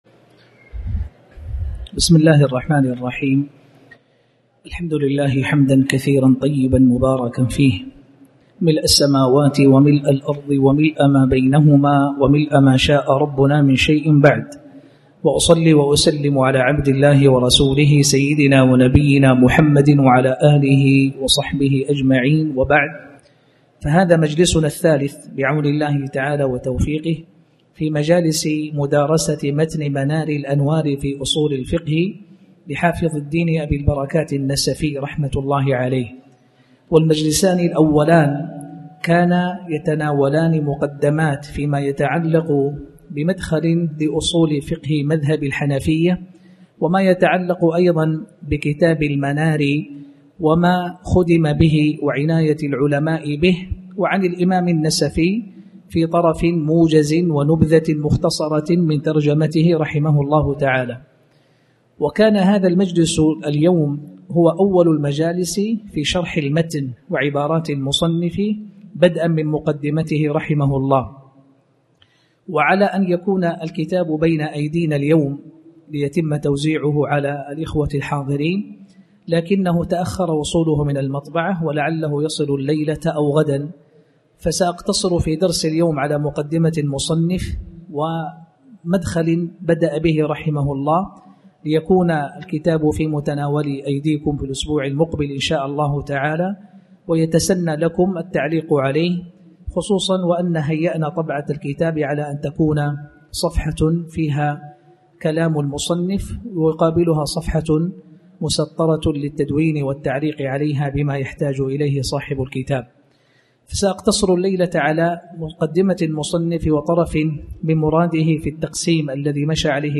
تاريخ النشر ٢١ محرم ١٤٣٩ هـ المكان: المسجد الحرام الشيخ